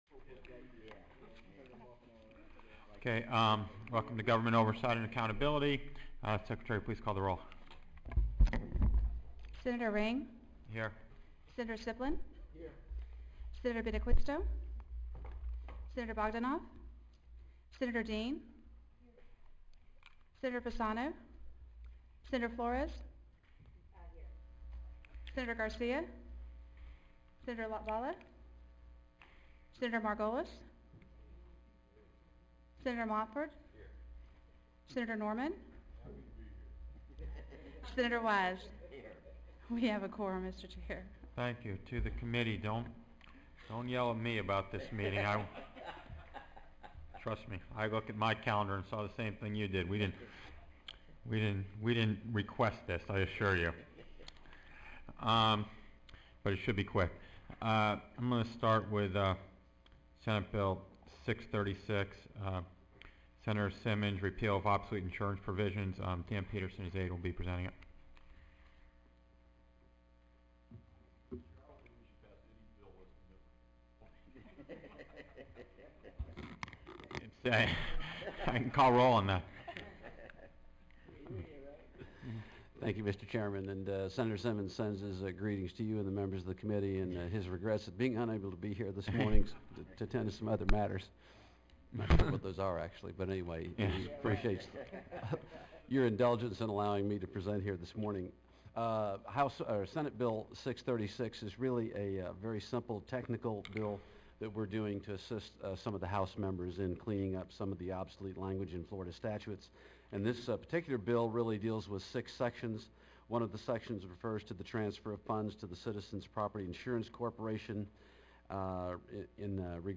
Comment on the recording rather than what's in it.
Budget Subcommittee on Criminal and Civil Justice Appropriations Location: 37 Senate Office Building Meeting Records Meeting Notice [PDF] Meeting Packet [PDF] Attendance [PDF] Expanded Agenda [PDF] Audio [MP3]